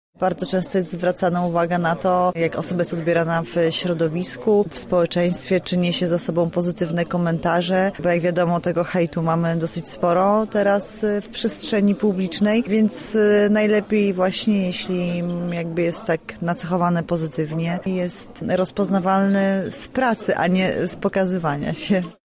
Była to jubileuszowa 70. edycja wydarzenia.
Gościem specjalnym została Anita Sokołowska, twarz znanej marki. Aktorka zdradziła, jakie cechy powinna mieć osoba, która pełni taką funkcję: